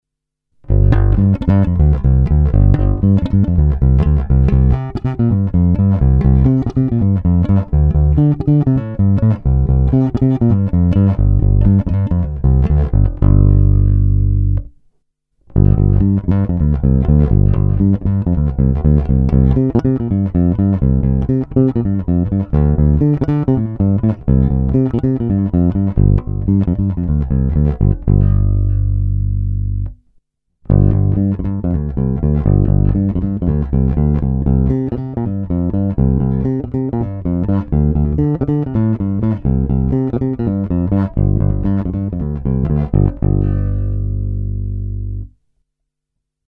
Není-li uvedeno jinak, následující nahrávky byly provedeny rovnou do zvukové karty a dále kromě normalizace ponechány v původním stavu.